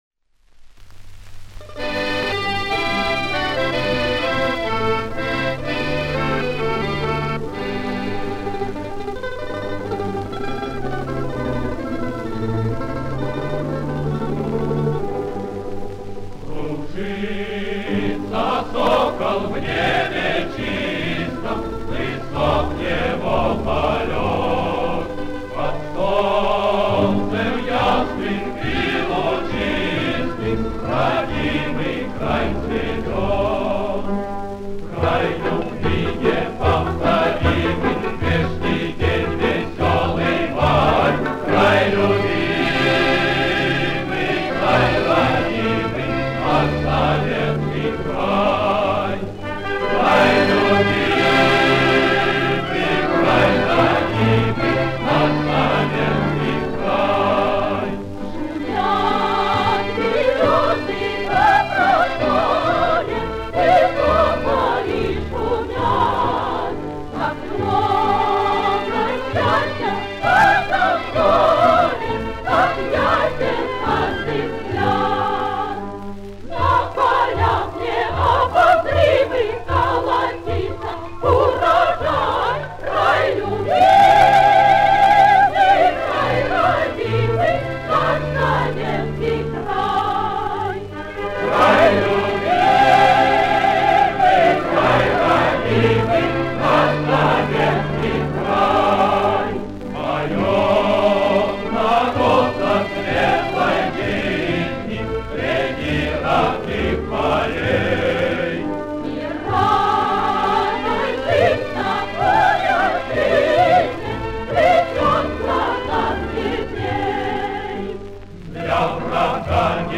джаз-оркестр под упр.